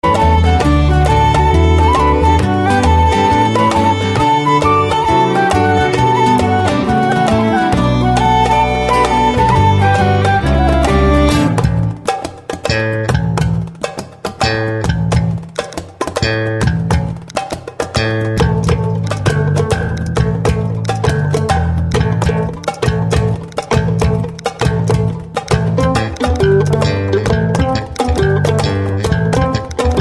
Branle de Noirmoutier
et musique par Emsaverien sur le CD Secondes vendanges en 2003. (